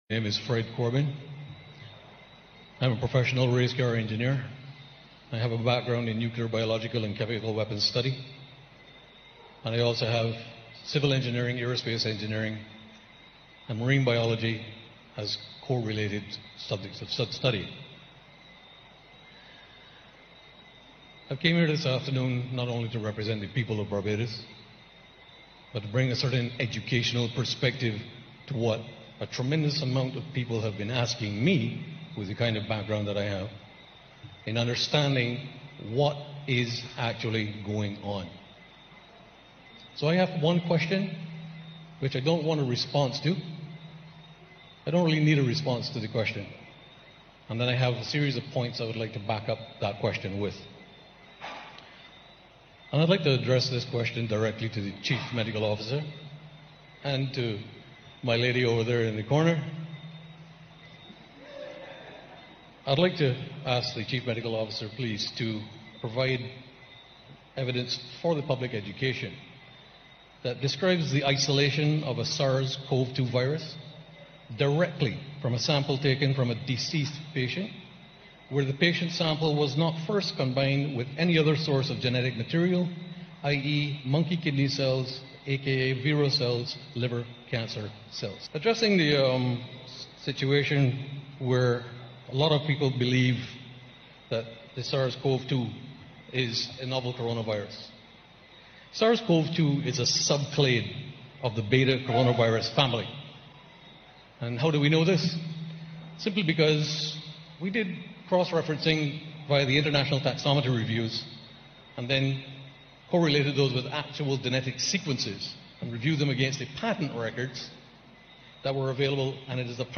Rede vor dem Senat von Barbados zur erweiterten Zulassung des Impfstoffe von Pfizer bei einer öffentlichen Befragung (ein paar Tumulte des Originalvideos sind herausgeschnitten).